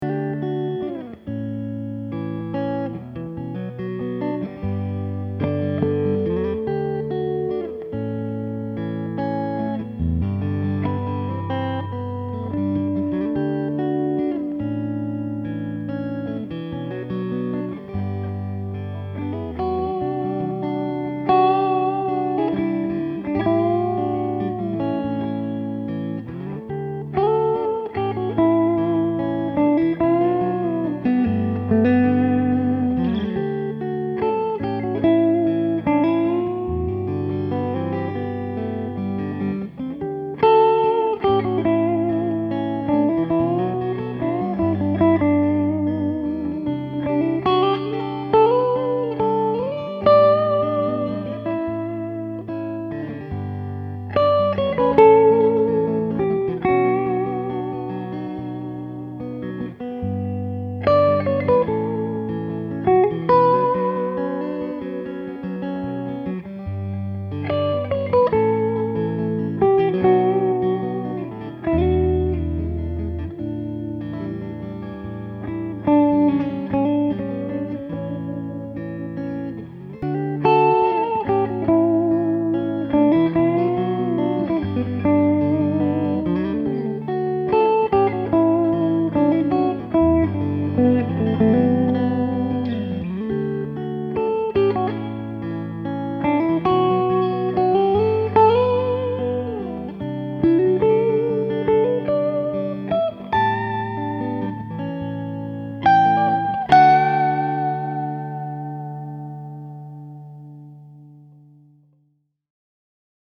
Came up with a simple chord progression in D, recorded it, then laid down the lead. The “rhythm” part of this clip is with the neck pickup coil-tapped. The lead is with the guitar in the middle position, no coil-tapping.
One of the first things that struck me was the super-organic, acoustic quality of the rhythm track.
Though I ultimately recorded the lead in a single take, I recorded it after about an hour of looping through the chord progression, just playing lead lines.